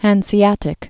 (hănsē-ătĭk)